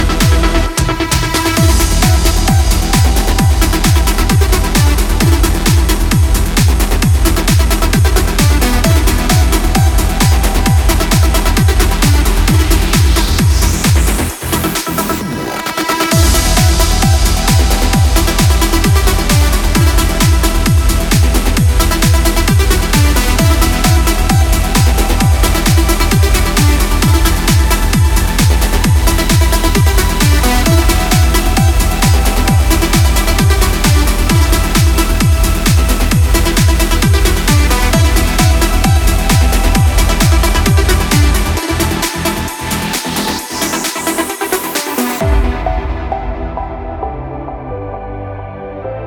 مثل همیشه پر انرژی و عالی